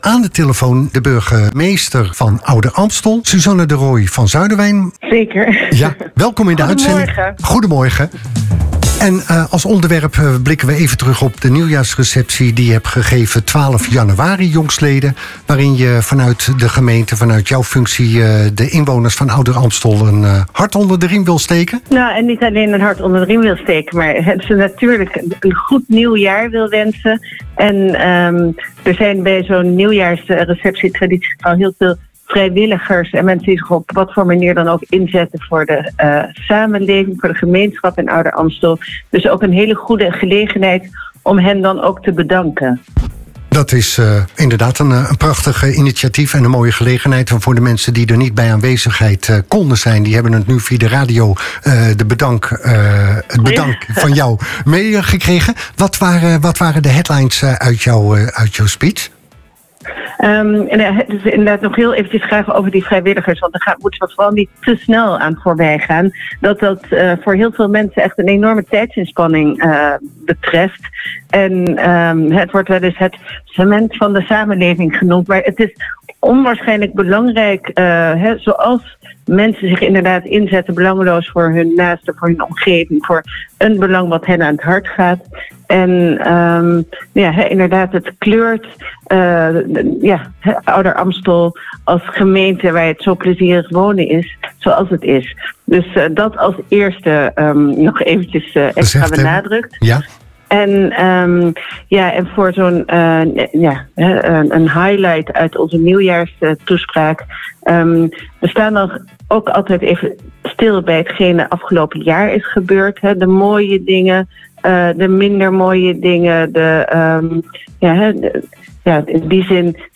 In Start Me Up sprak Jammfm met burgemeester Susanne de Roy van Zuidewijn. In het gesprek keek zij terug op de nieuwjaarsreceptie van 12 januari en stond zij stil bij wat het afgelopen jaar heeft gebracht voor de gemeente Ouder-Amstel, maar ook bij de momenten die het komende jaar extra betekenis geven.